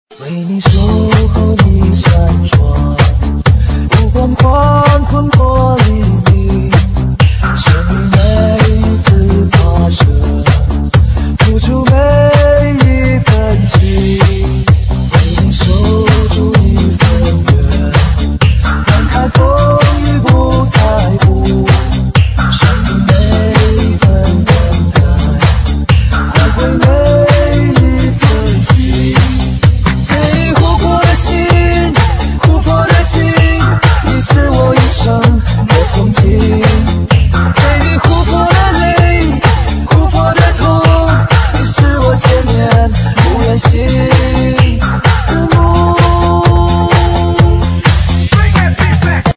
分类: DJ铃声
瑞典女声DJ